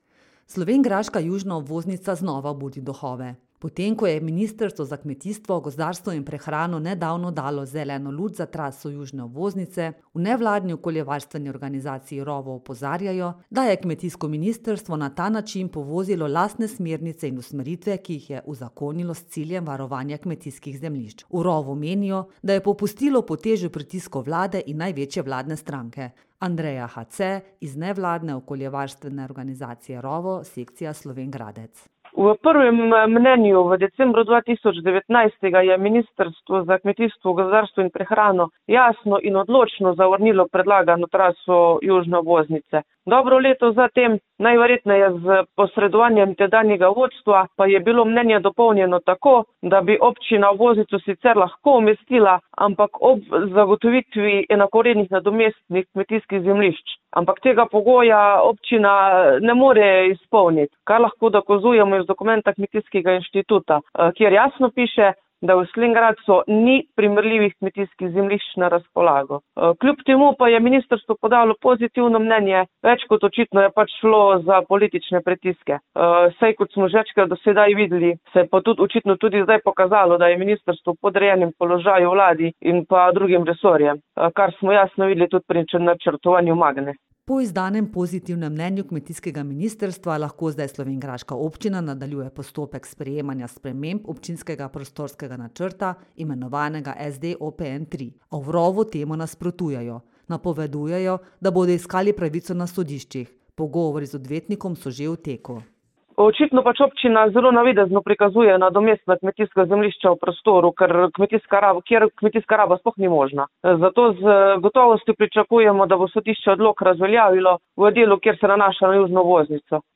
prispevek Rovo.mp3